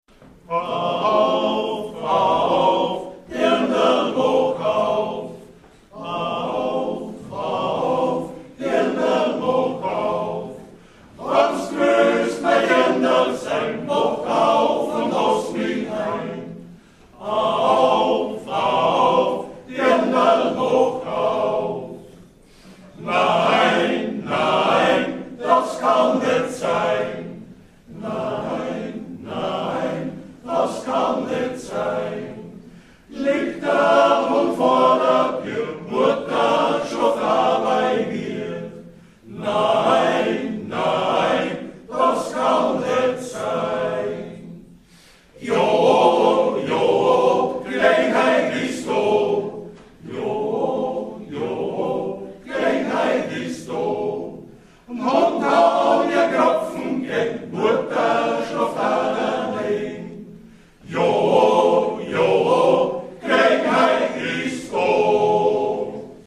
Am Samstag, dem 19. Juni 2010 fand das diesjährige Bezirkssingen statt.
Neun sängerbundangehörige Chöre aus dem Bezirk Hartberg, darunter der Männergesangverein Kaindorf, traten mit je 2 Liedern auf.